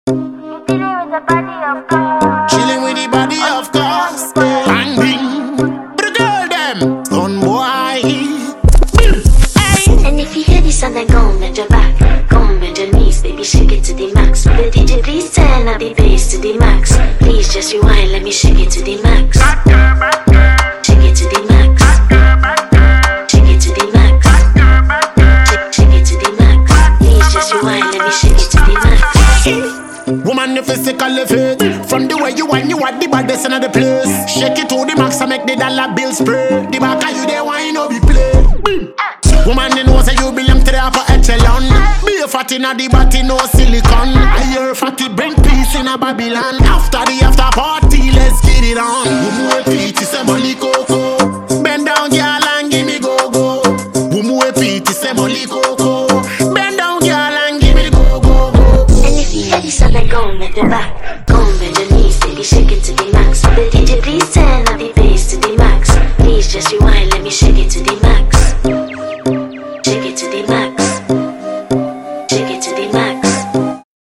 hard-hitting freestyle
blending dancehall flair with afrobeat vibes